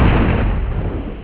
1 channel
explosion2.wav